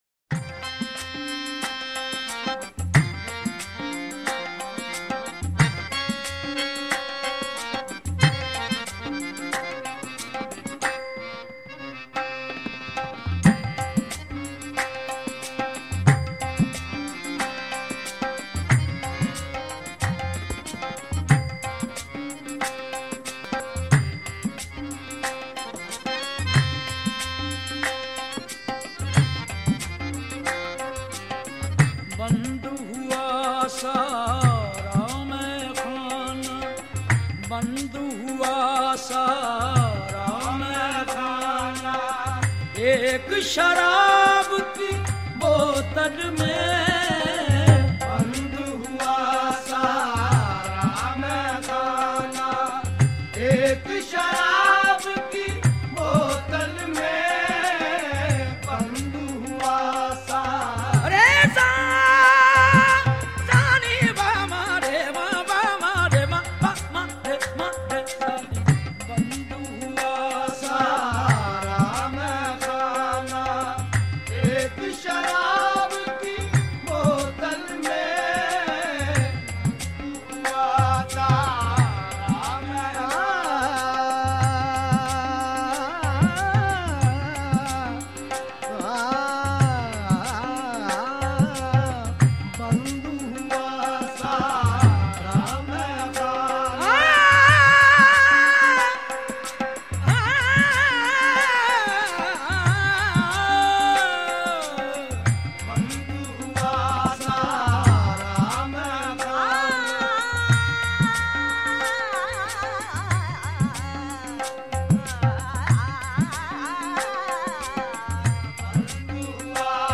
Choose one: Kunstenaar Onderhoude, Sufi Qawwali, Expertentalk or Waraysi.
Sufi Qawwali